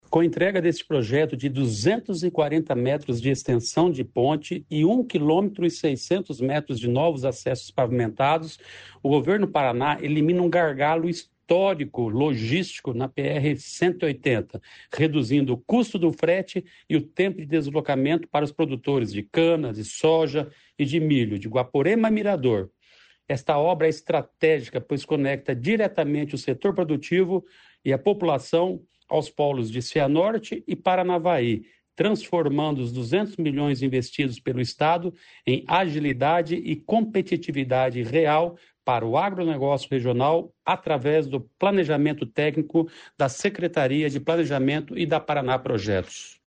Sonora do secretário do Planejamento, Ulisses Maia, sobre nova ponte no Rio Ivaí